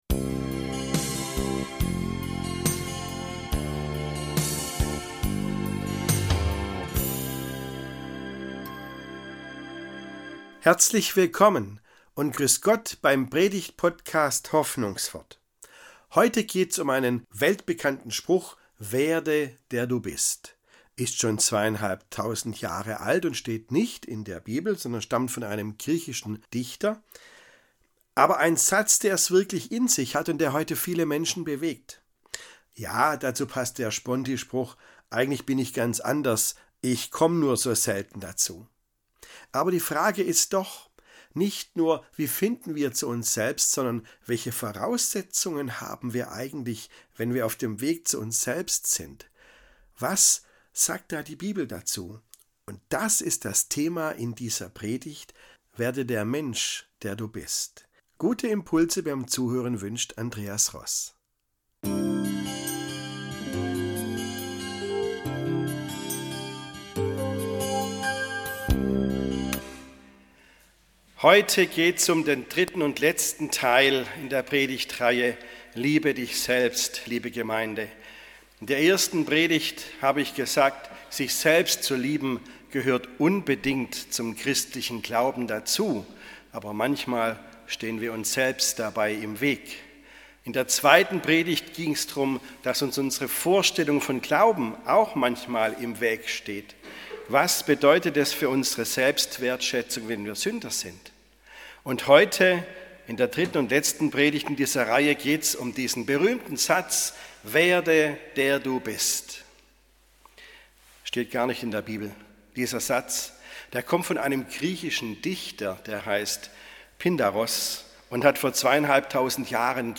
Werde, der du bist ~ Hoffnungswort - Predigten